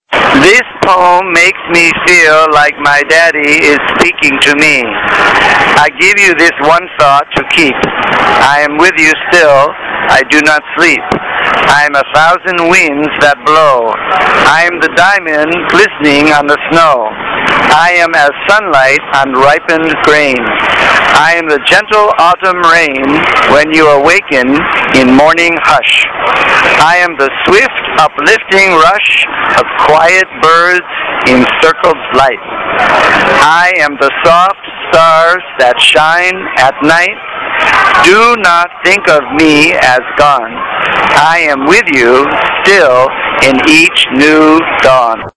嘉義市
文化路
為同學朗讀一首為九一一事件哀悼的小詩。